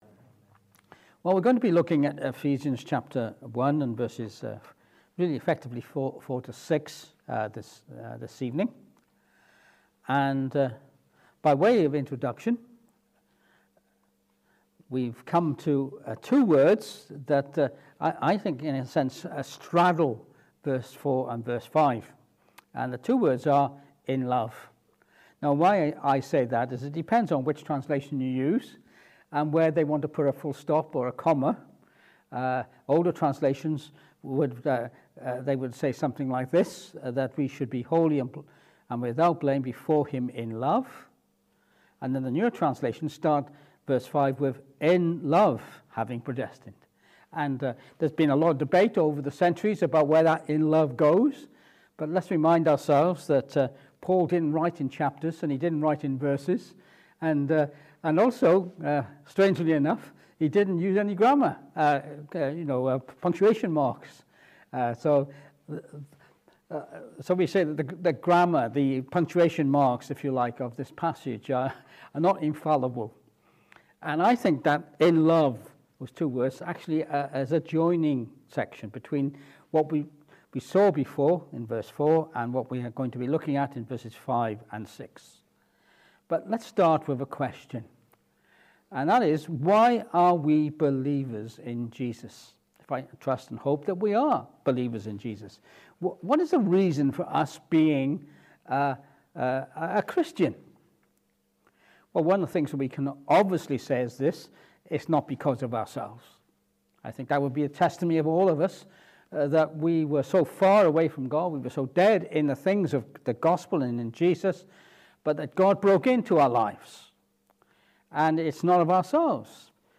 Ephesians 1:4-6 Service Type: Evening Service This evening we return to Ephesians chapter 1 and verses 4-6.